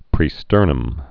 (prē-stûrnəm)